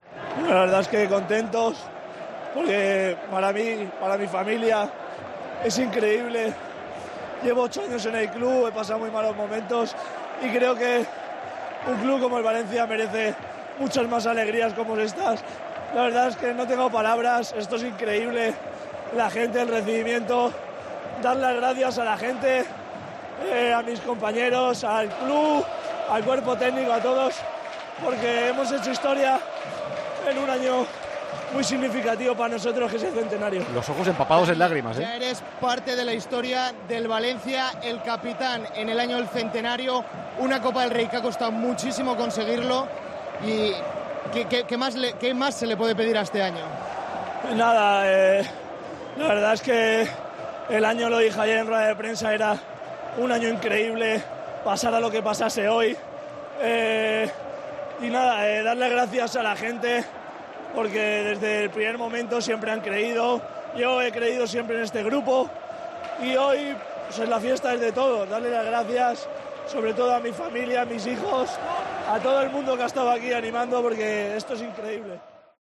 El capitán del Valencia rompió a llorar, tras ganar la Copa del Rey.